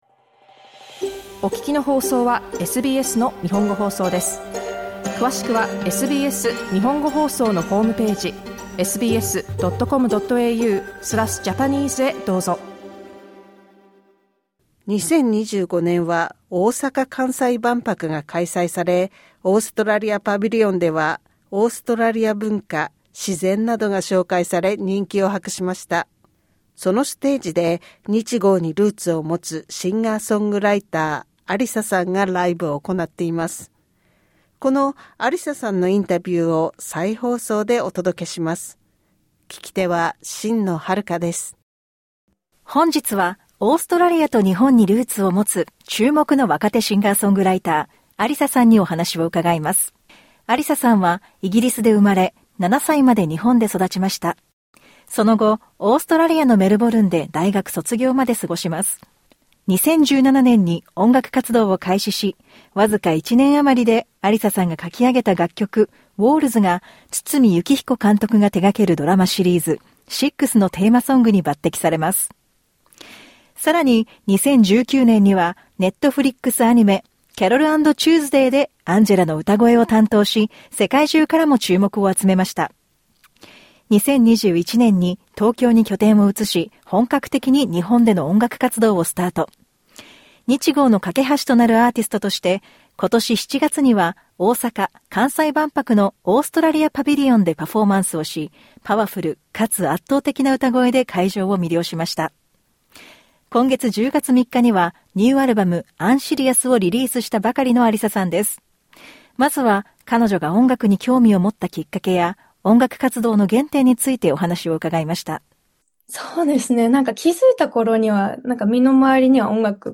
インタビューを再放送でお届けします。